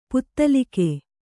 ♪ puttalike